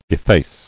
[ef·face]